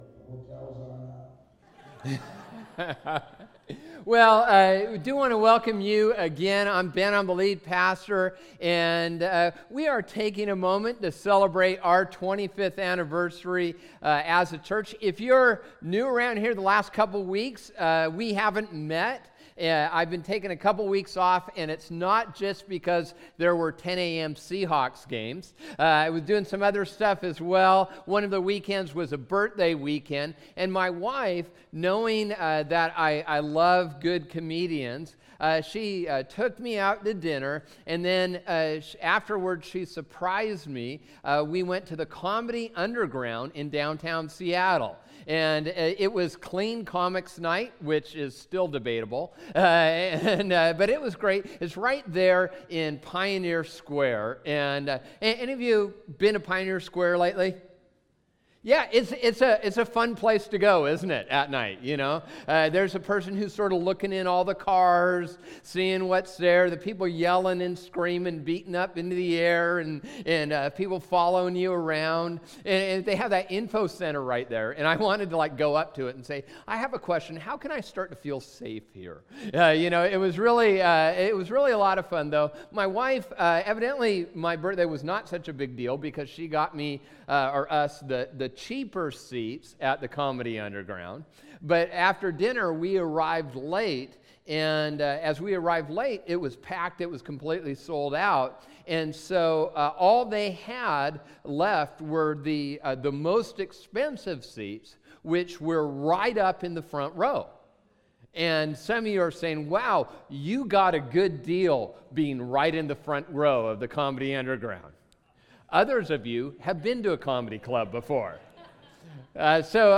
Date: 11/02/14 Message begins at 15:58